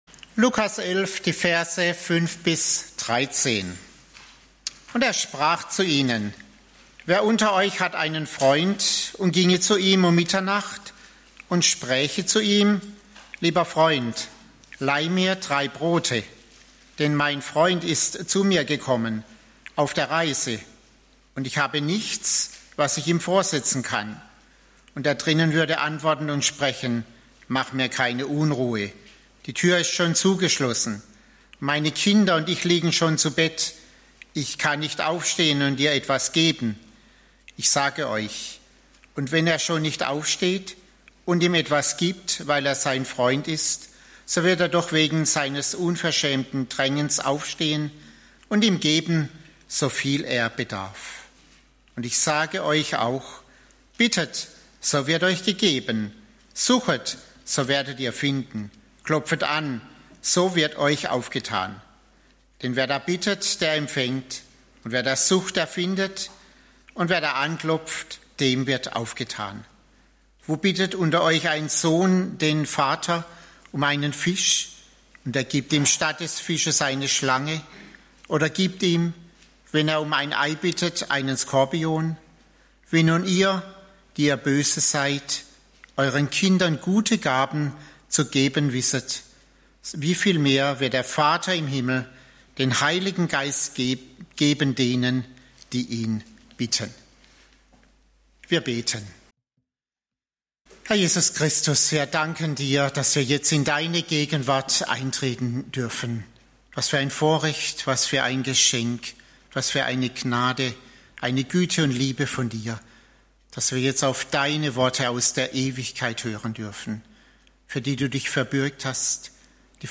Gottesdienste und Bibelstunden der Langensteinbacher Höhe